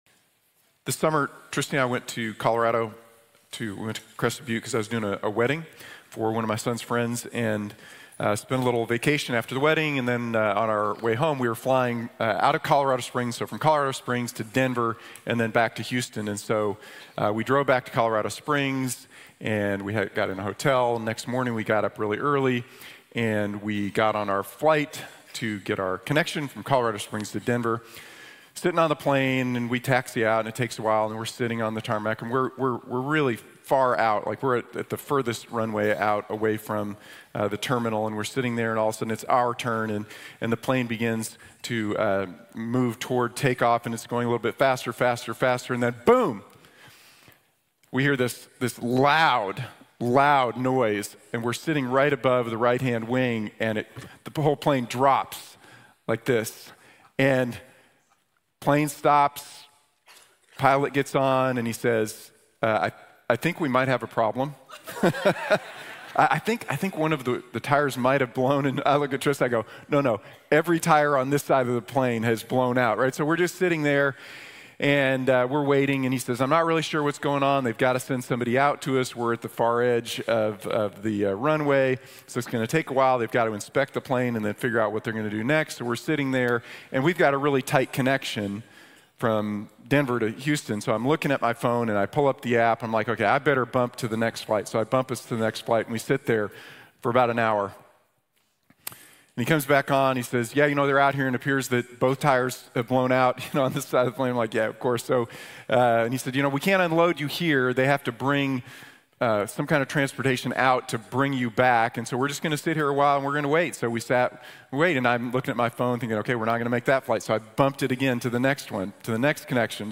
Trust and Obey | Sermon | Grace Bible Church